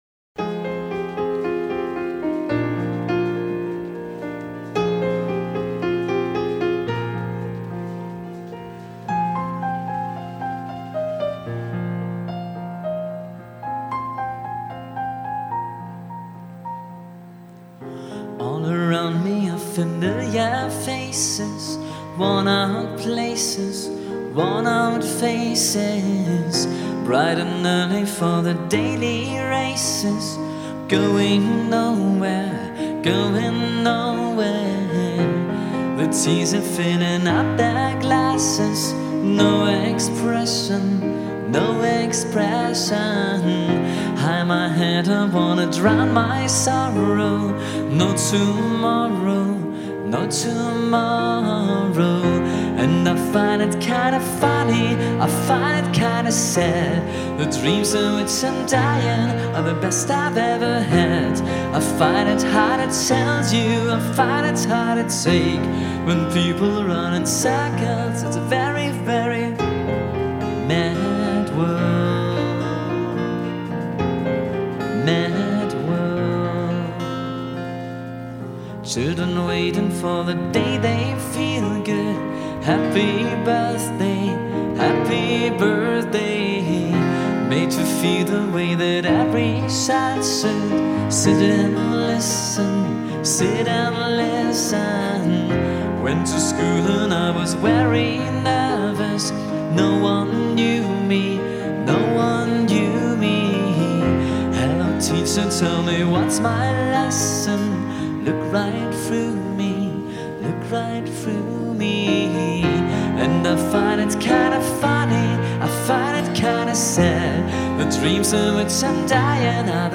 Livemusik – Gesang, Gitarre und / oder Klavier
live und unplugged, ohne doppelten Boden.
Mal loungig entspannt, mal rockig